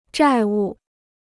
债务 (zhài wù): debt; liability.